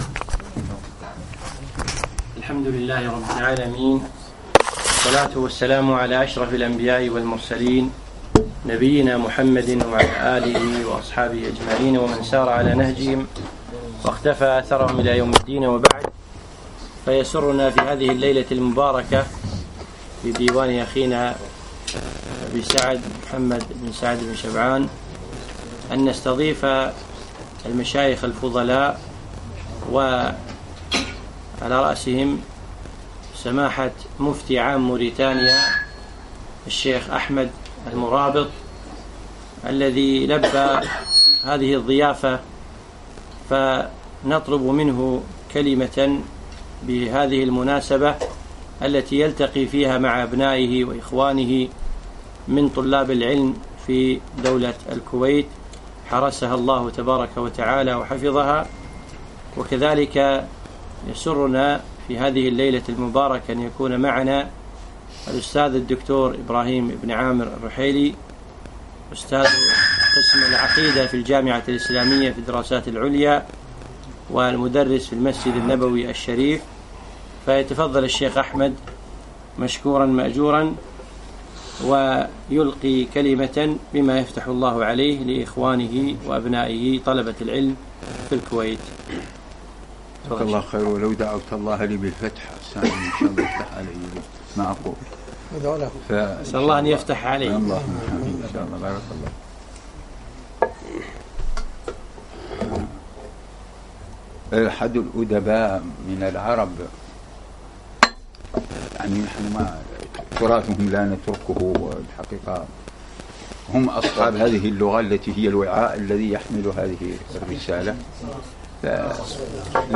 كلمة عامة - بمشاركة مفتي موريتانيا الشبخ أحمد المرابط